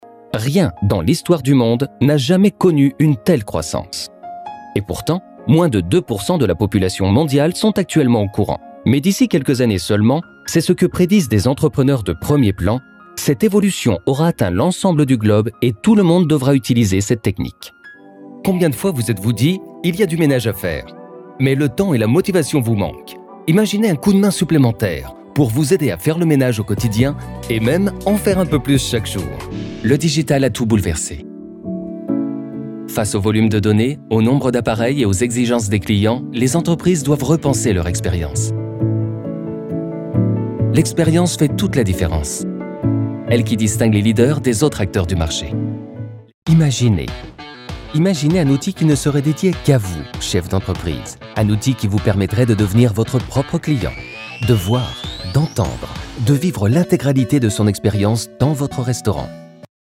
Démos Institutionelles
Les démos sur cette page sont des extraits de projets réels livrés et mixés par les studios respectifs.